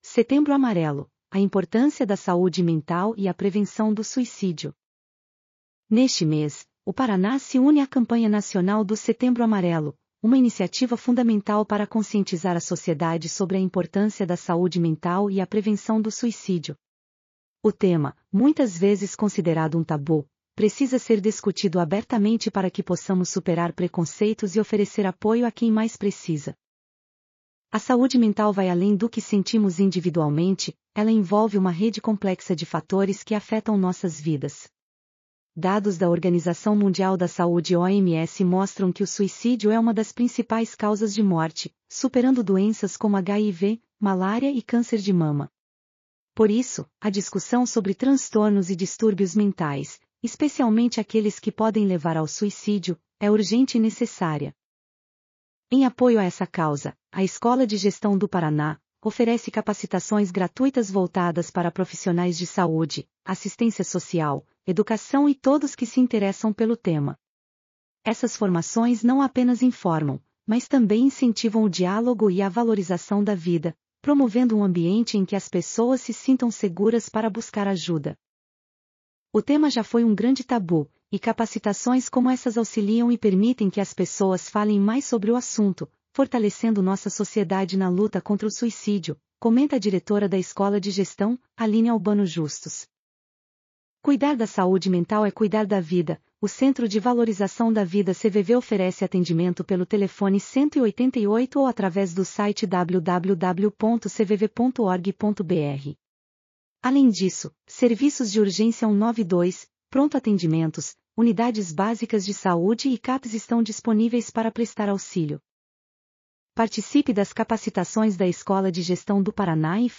audionoticia_setembro_amarelo.mp3